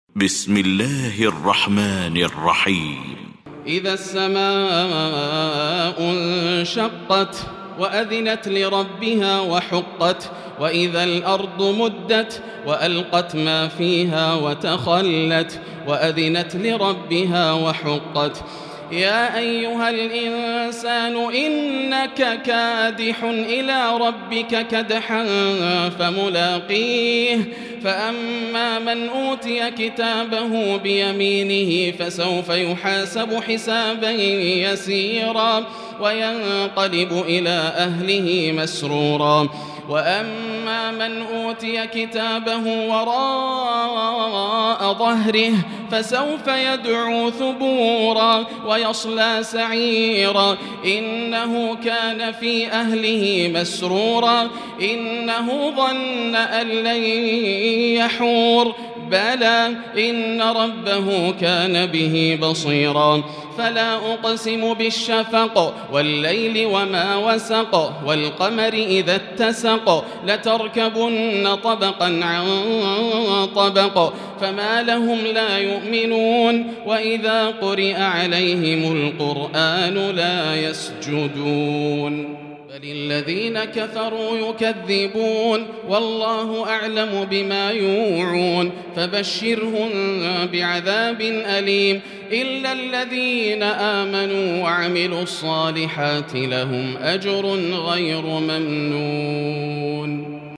المكان: المسجد الحرام الشيخ: فضيلة الشيخ ياسر الدوسري فضيلة الشيخ ياسر الدوسري الانشقاق